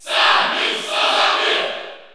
Category: Crowd cheers (SSBU) You cannot overwrite this file.
Zero_Suit_Samus_Cheer_French_PAL_SSBU.ogg